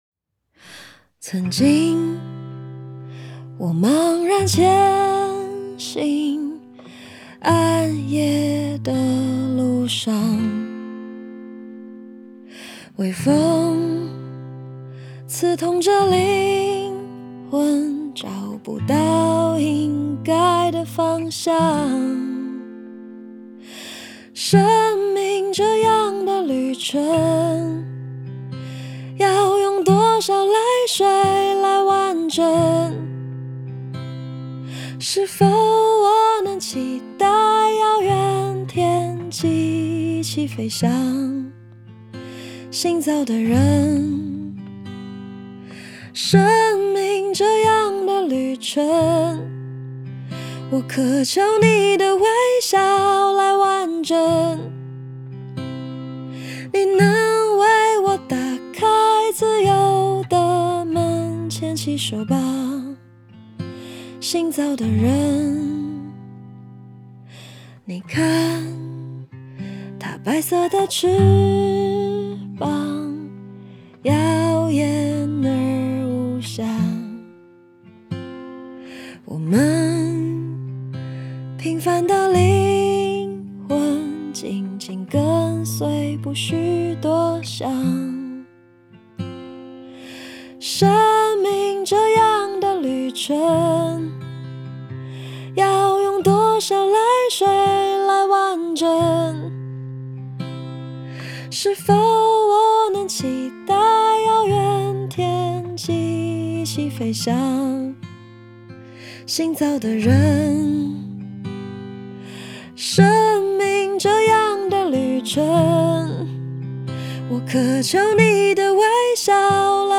在线试听为压缩音质节选，体验无损音质请下载完整版